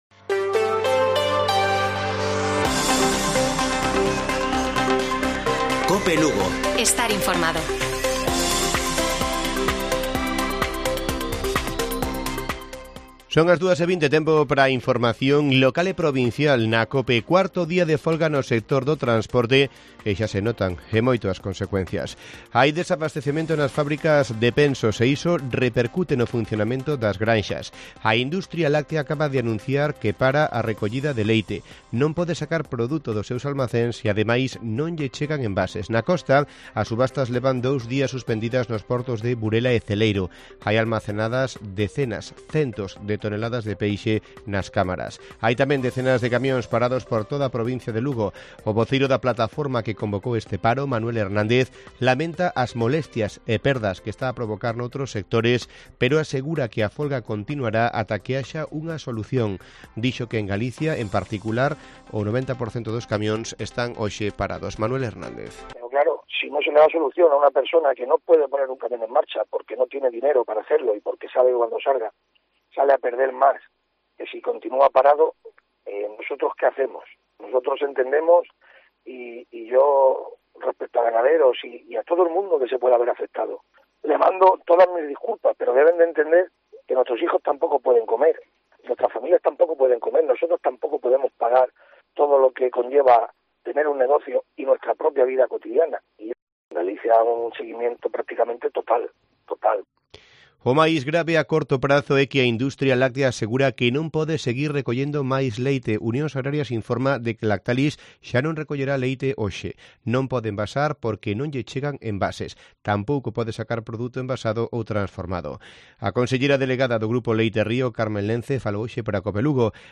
Informativo Mediodía de Cope Lugo. 17 de marzo. 14:20 horas